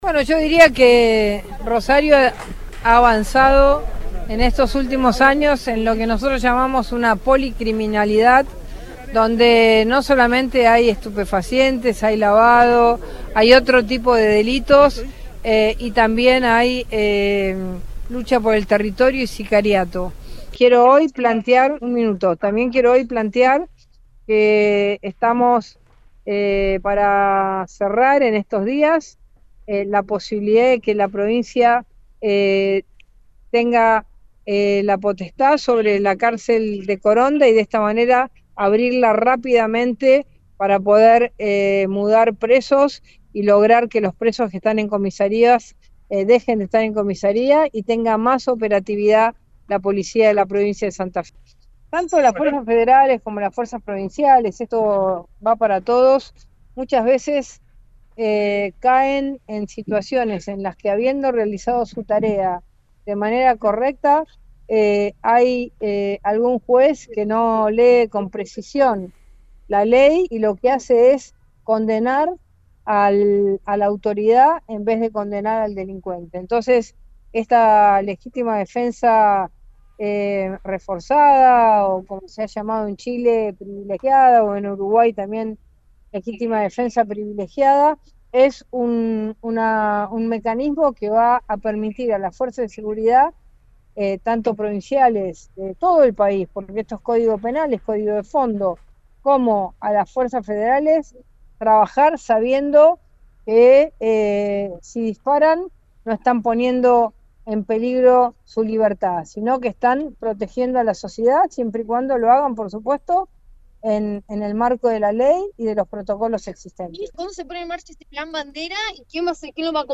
En un acto que tuvo lugar en el Monumento a la insignia patria, la ministra de Seguridad de la Nación y el gobernador plasmaron lo acordado días antes en Buenos Aires para el sur santafesino.